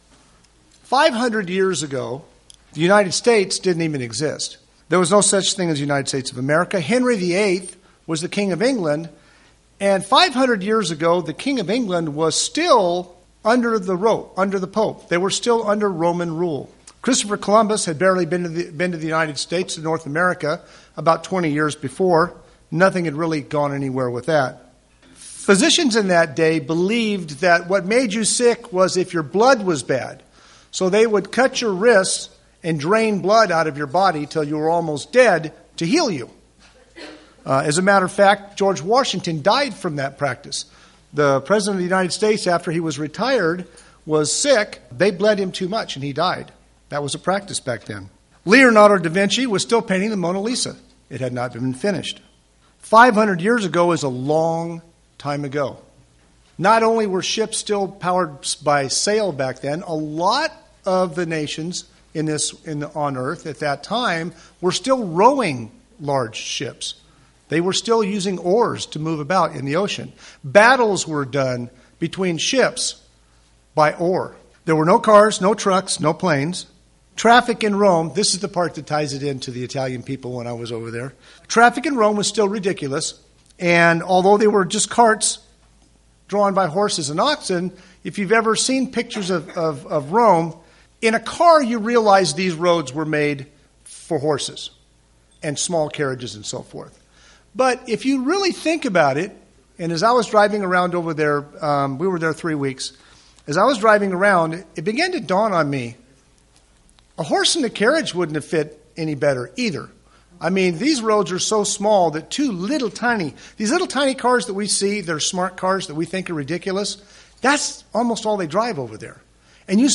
Given in Redlands, CA
and church we know today UCG Sermon Studying the bible?